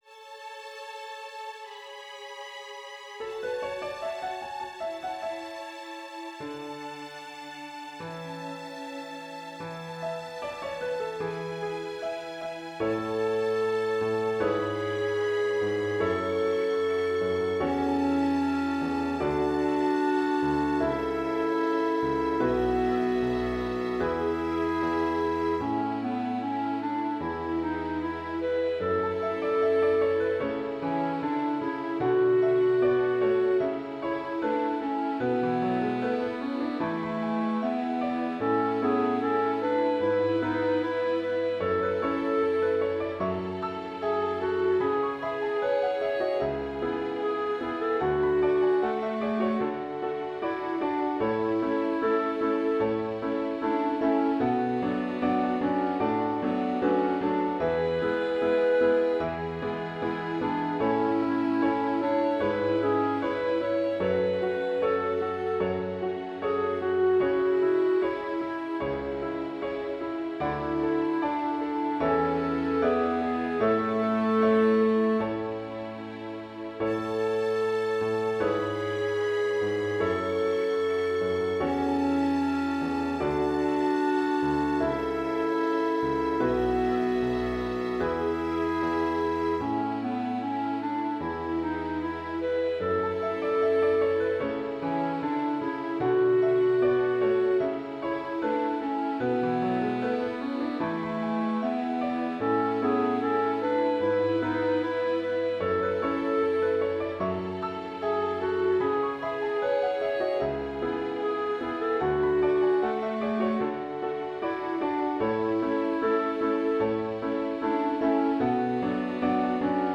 + počítačové verzie niektorých našich úprav 😉